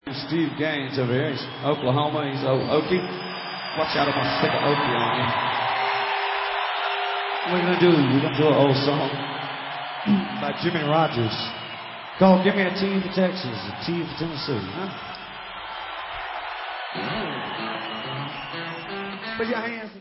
-Live At Fox Theatre, 2001
sledovat novinky v oddělení Southern (jižanský) rock